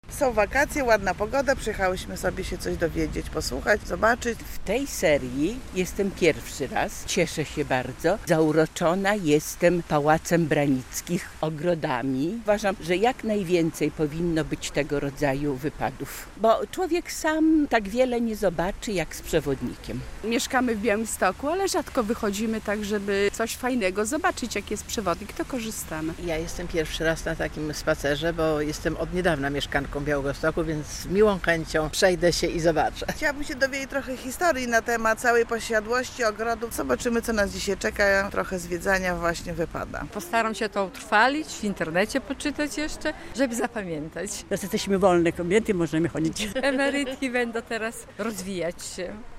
Spacer tematyczny dla seniorów "Podlaski Wersal" - relacja